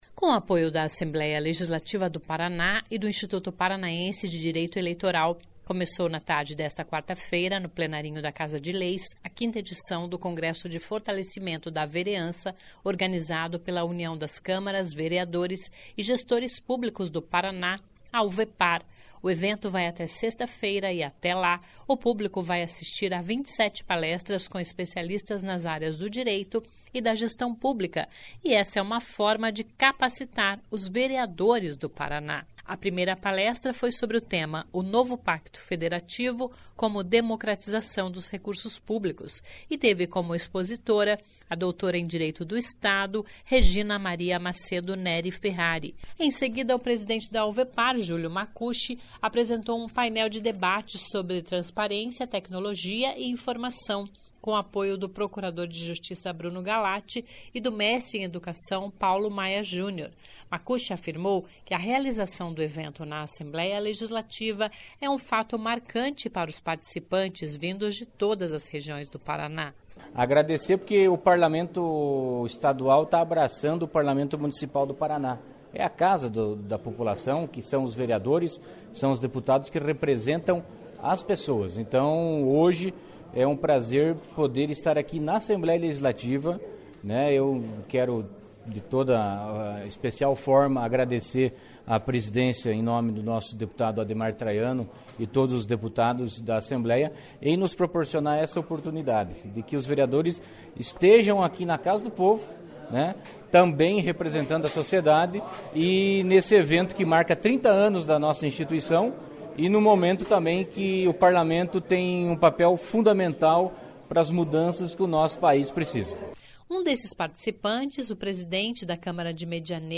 Um desses participantes, o presidente da Câmara de Medianeira, no Oeste do estado, Valdecir Fernandes (PSDB), diz que estar sempre se reciclando é dever do vereador.